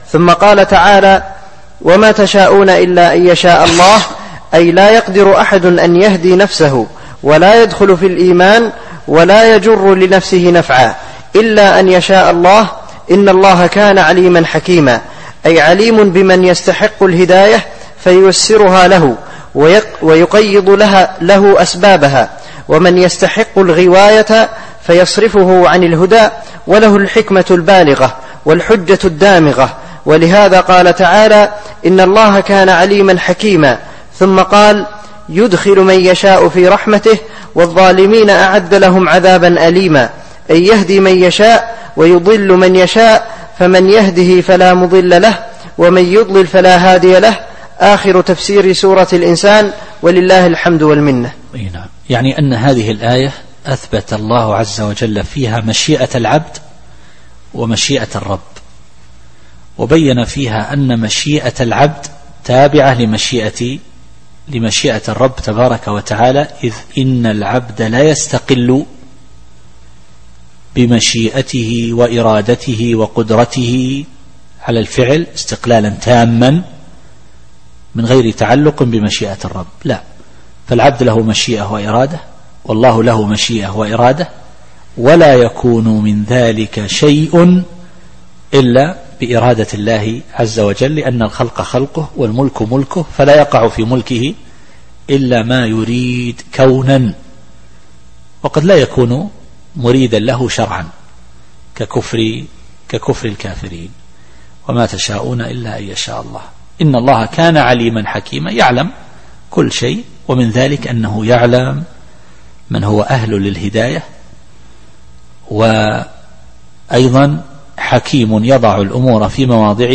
التفسير الصوتي [الإنسان / 30]